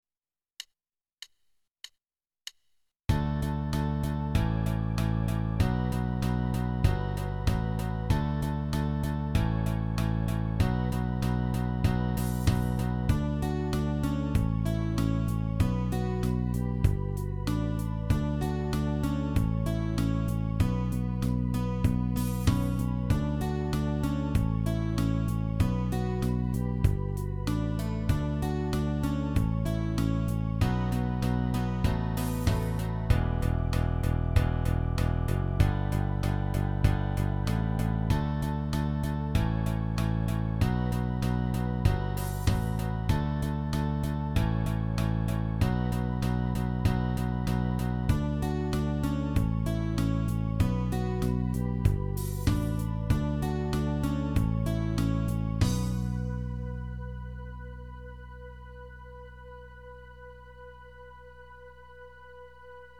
music (MIDI to MP3)